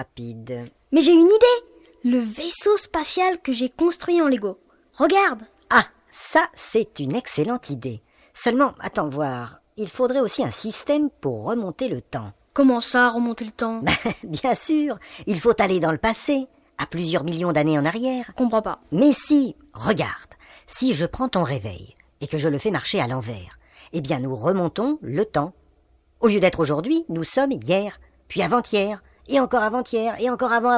Cet album propose un conte musical et un conte pédagogique.